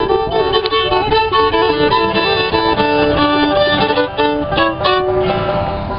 D’Addario Kaplan Violin Strings
The tone?  Phenomenal!